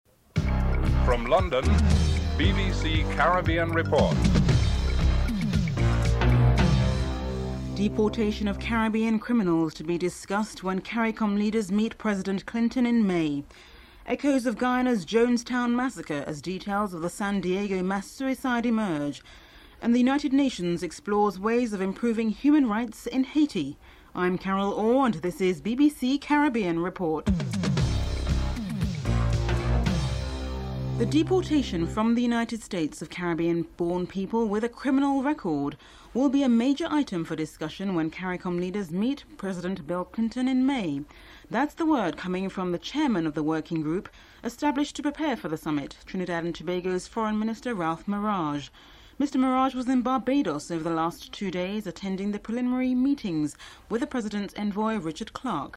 1. Headlines (00:00-00:31)
Ralph Maraj, Trinidad and Tobgao Foreign Minister is interviewed.
Owen Bennett Jones reorts from Geneva (11:06-13:10)